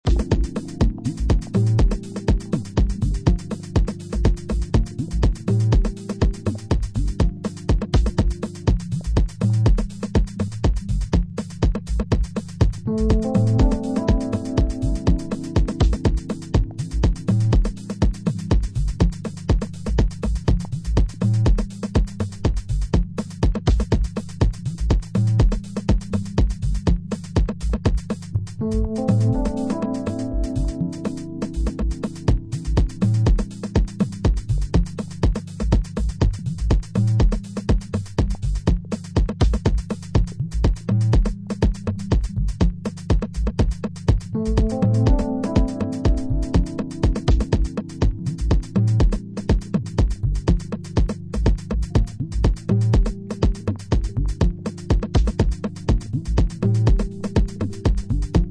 stripped-down analog Detroit